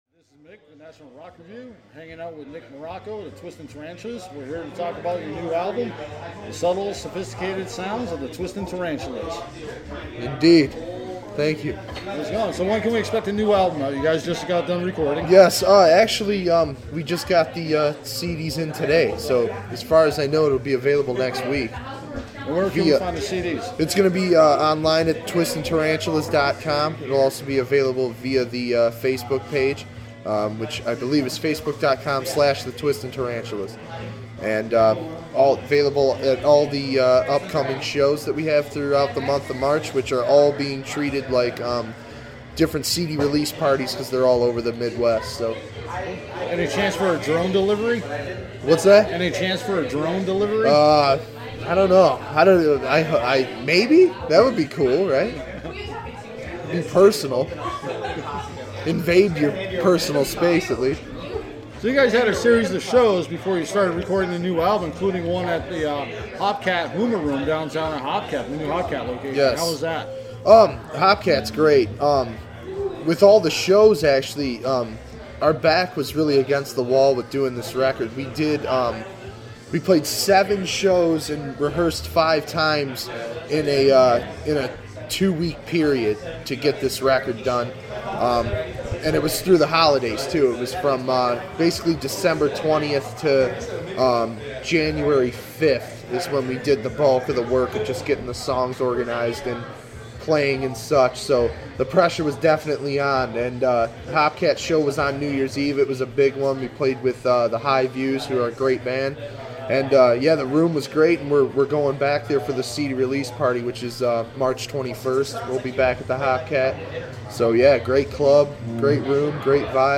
Jump Blues Rockabilly Punk style